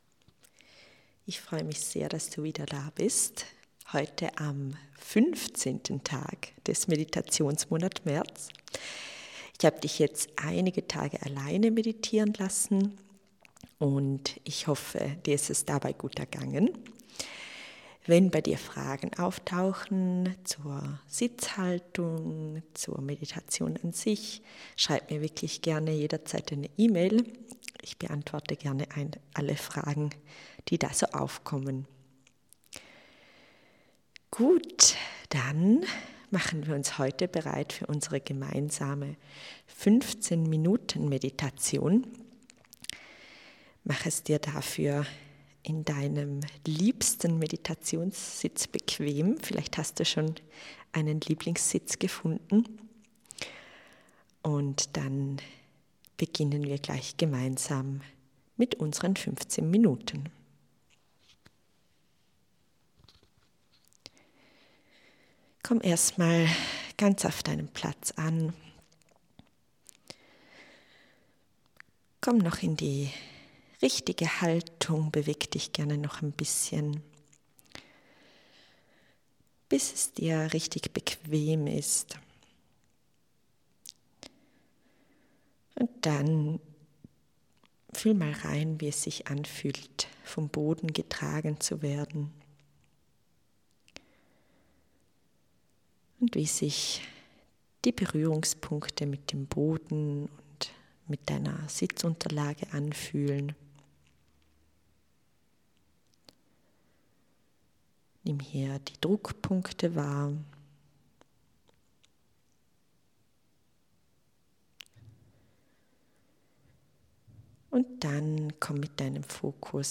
Deine Meditation